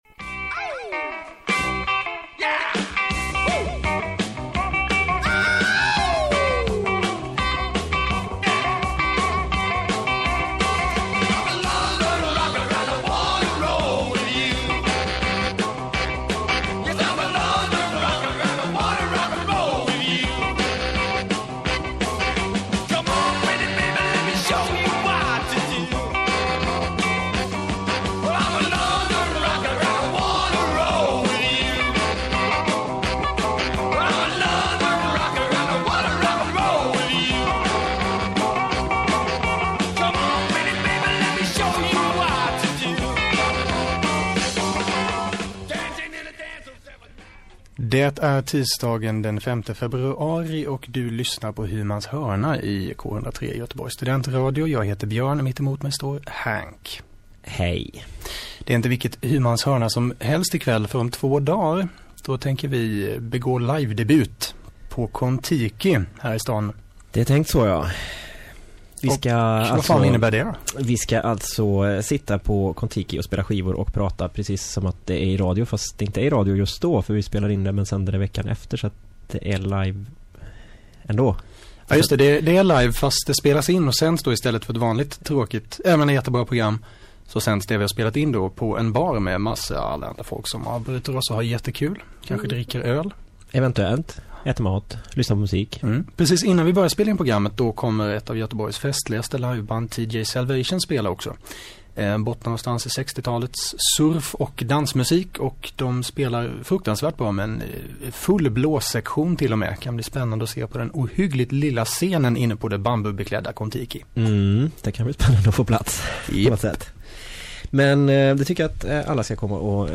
Kvällens program handlade om att vi ska ha en klubbkväll på Kontiki på torsdag den 7 februari! Ja, vi spelade lite musik också...